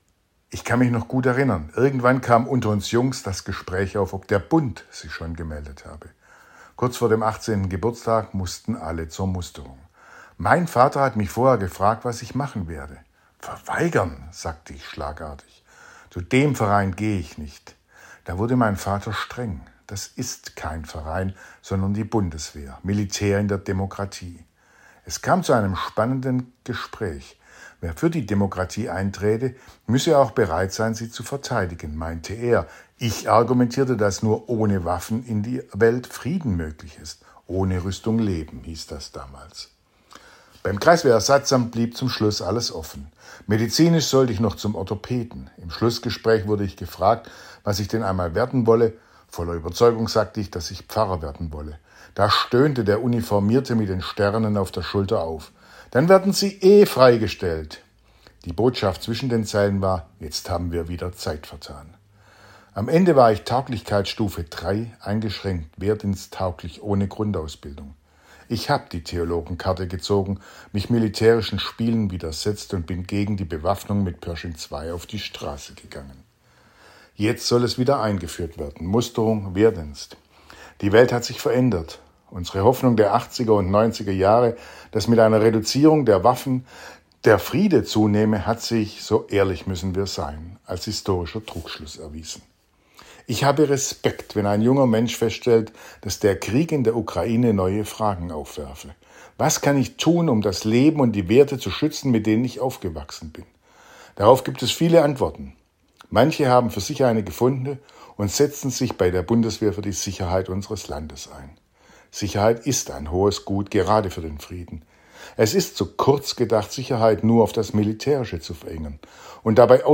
Radioandacht vom 2. September